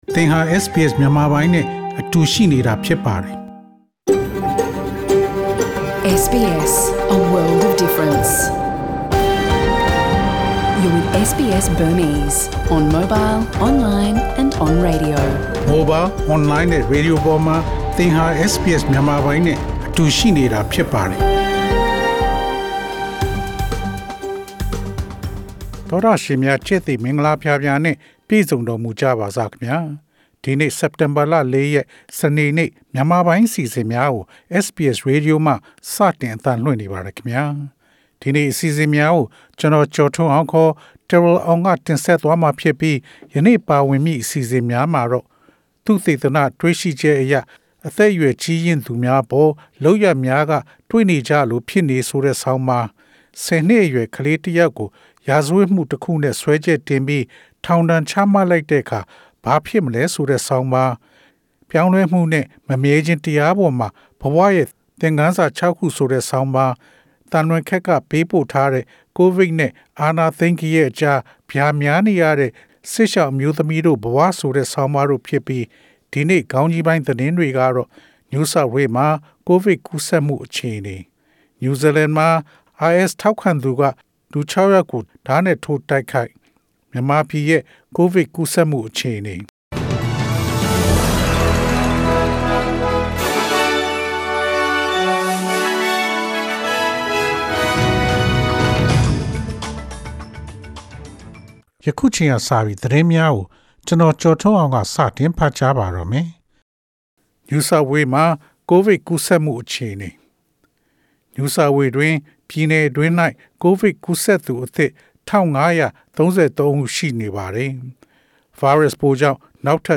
SBS မြန်မာပိုင်း အစီအစဉ် ပေါ့ကတ်စ် သတင်းများ။